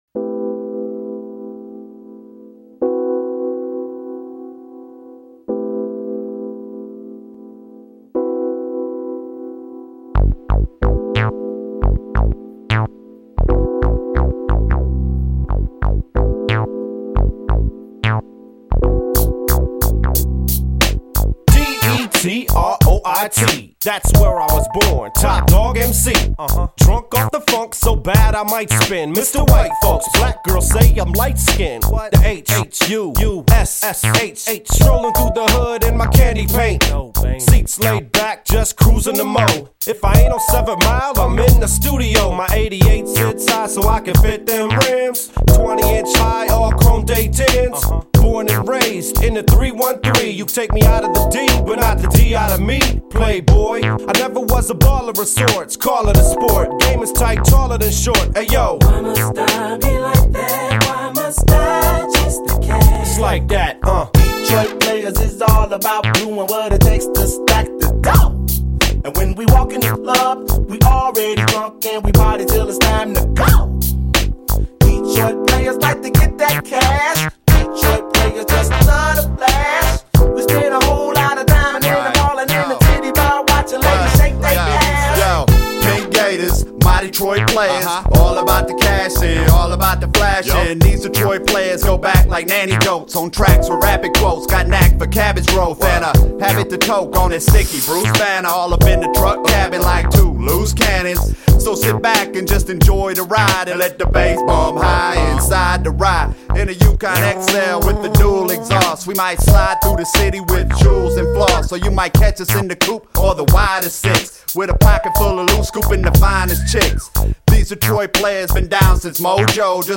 Раздел: Музыка » Hip-Hop